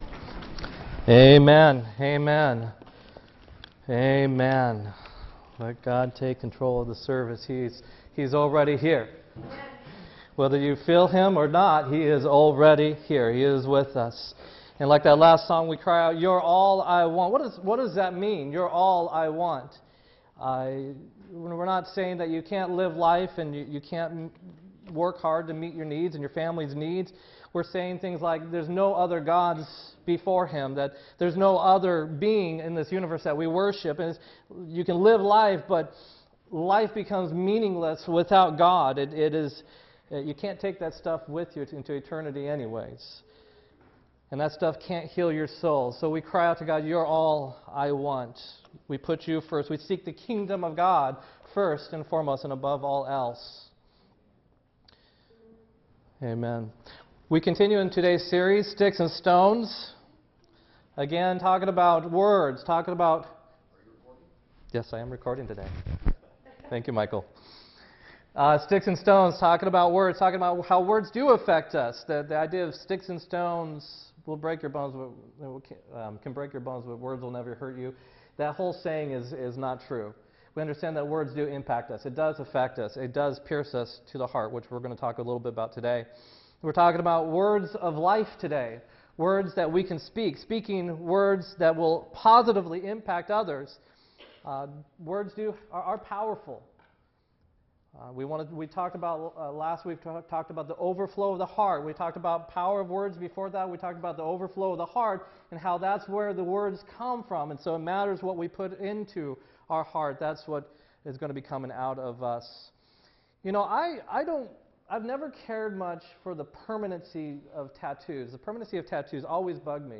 10-29-16-sermon